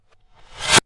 反向立管 " 立管03
描述：记录会议的不同影响逆转，以建立动画紧张。
Tag: 立管 冲突 reversic 冲击